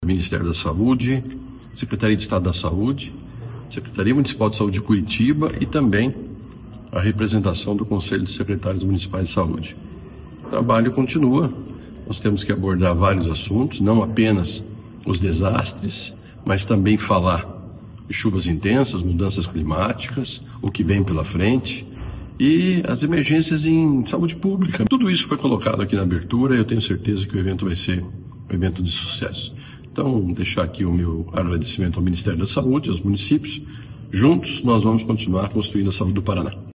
Sonora do secretário da Saúde, Beto Preto, sobre o simulado para resposta e vigilância em desastres por chuvas intensas